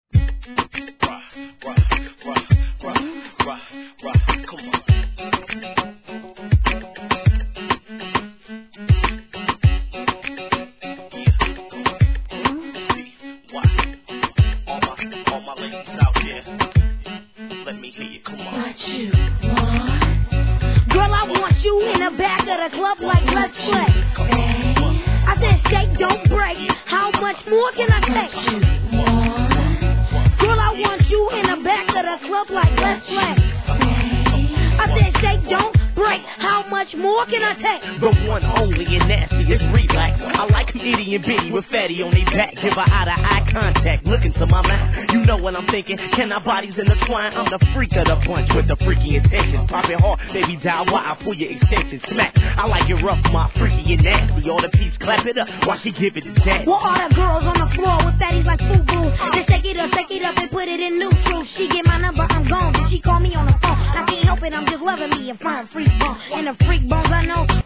G-RAP/WEST COAST/SOUTH
キッズ・ラッパーに悩ましげなエロい合いの手♪チキチキBOUNCE!!